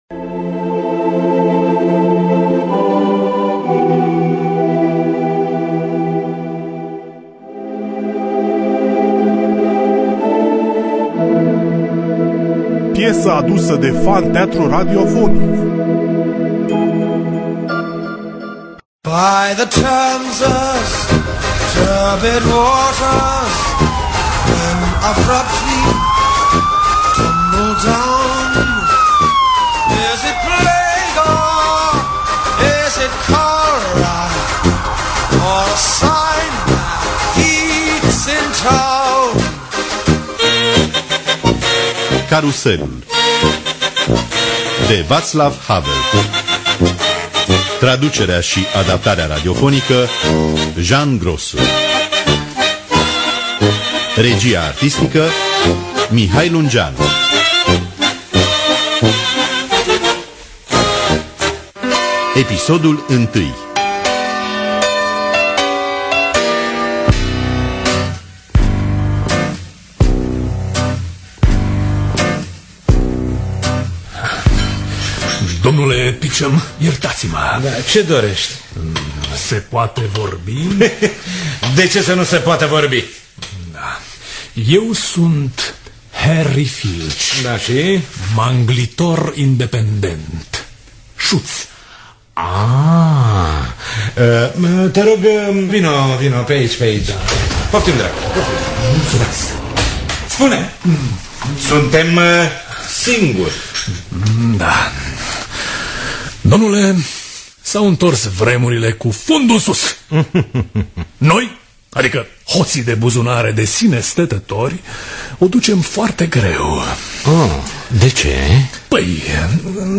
Traducerea și adaptarea radiofonică